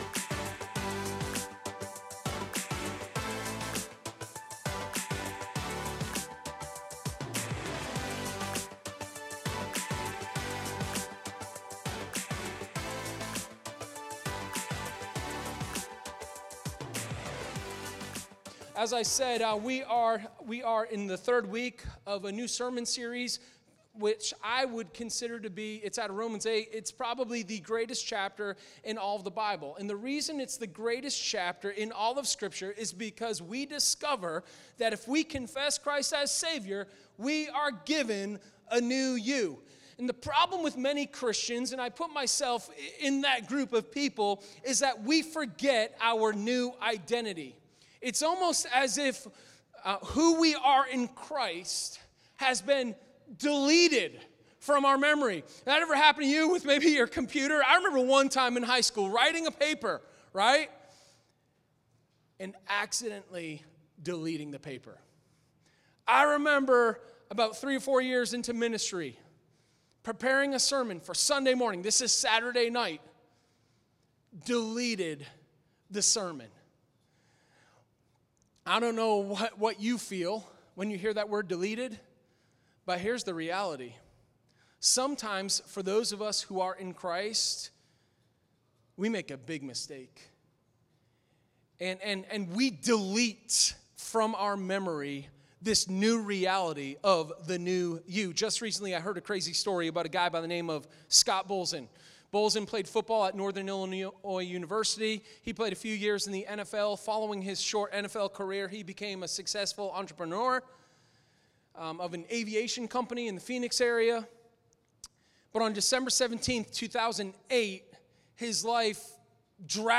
Sermon04_25_New-Hope.m4a